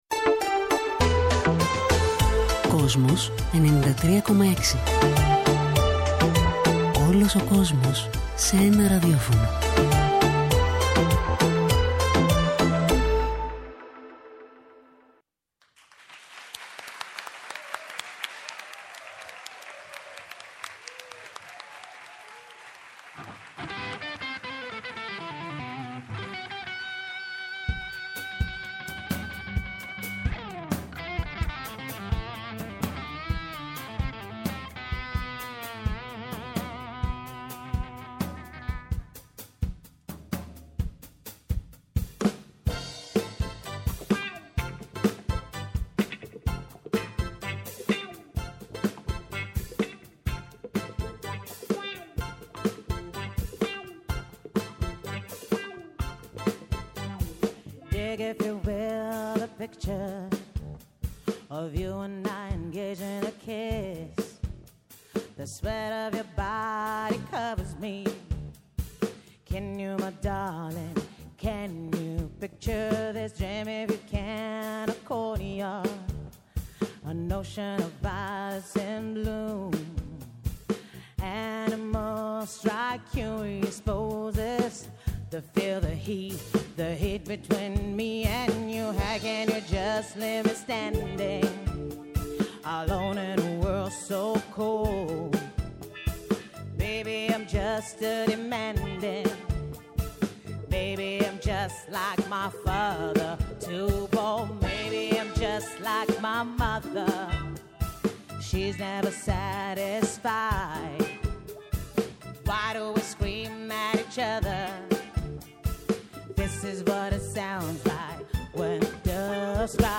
φωνή
πλήκτρα
κιθάρα
μπάσο
τύμπανα